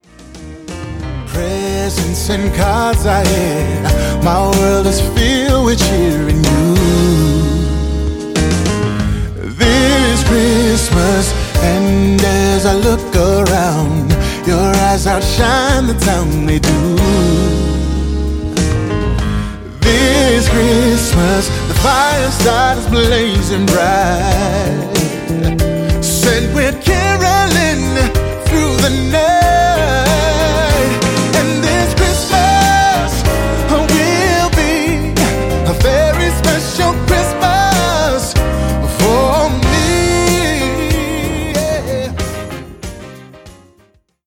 Internet sessions.